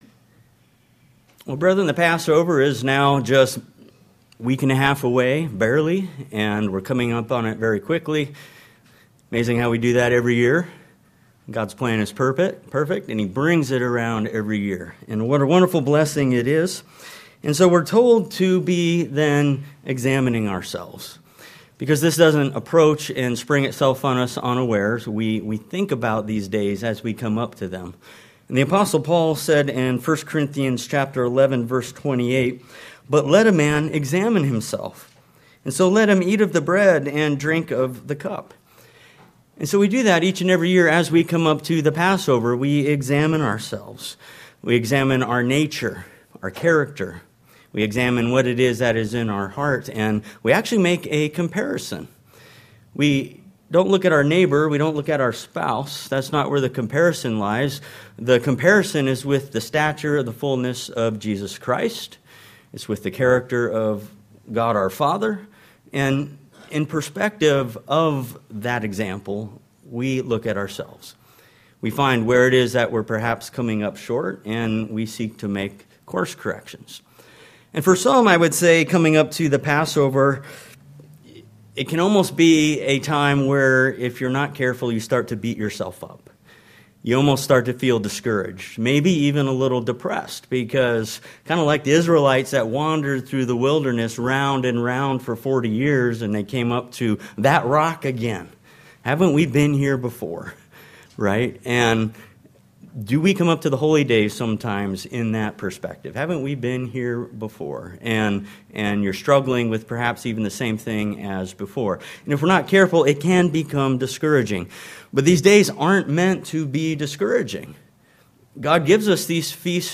This sermon also shows from the scriptures why the Passover is to be kept on the beginning of the 14th day of the first month according to the Old Testament record and in agreement with the timing in which Christ and His discipl